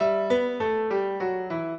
piano
minuet9-10.wav